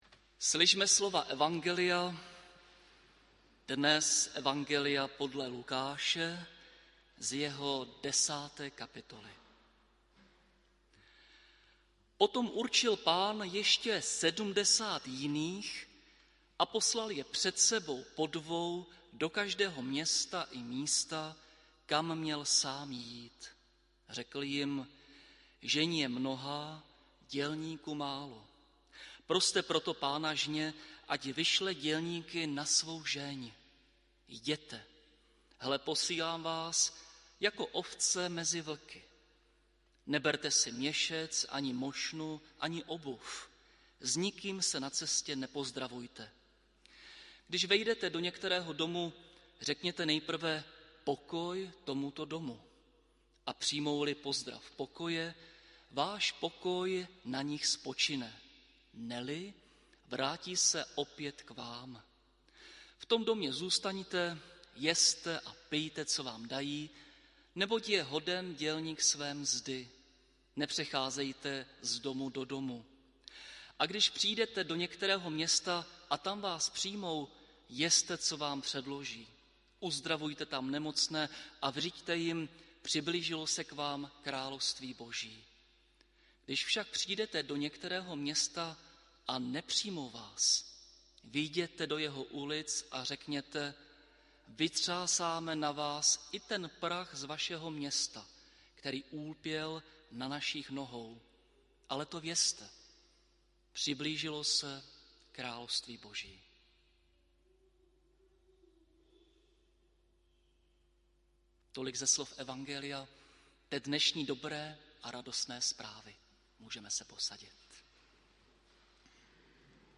Audio kázání na text z 10. kapitoly Lukášova evangelia zde